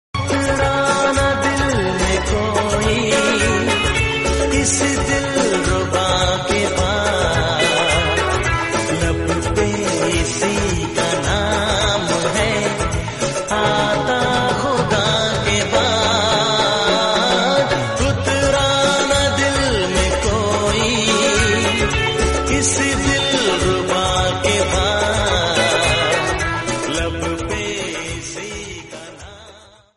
Enjoy high quality Bollywood sad romantic melody ringtone.